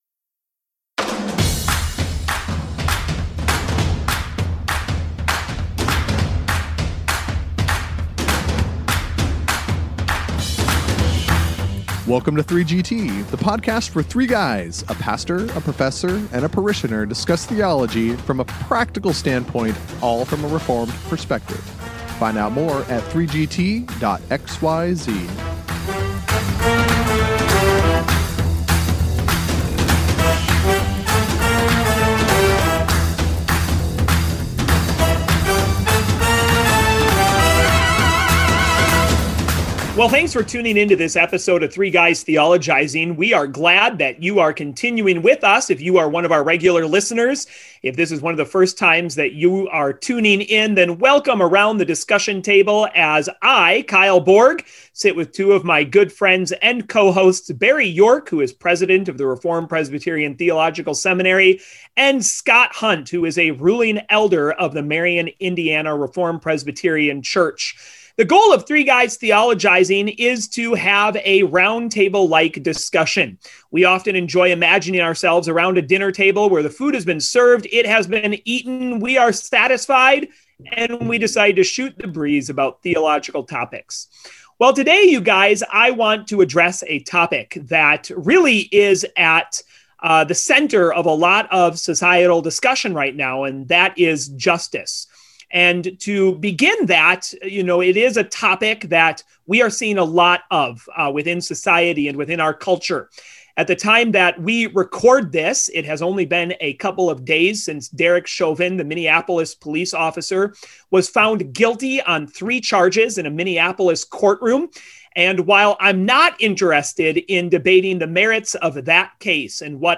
They discuss Biblically how justice is defined, and how it is essential to the character of God. Then, with their lawyer parishioner at the forefront, they consider how society views justice, the misperceptions of what constitutes justice, and societal factors that make justice difficult to pursue in the United States.